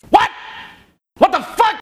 Worms speechbanks
Oops.wav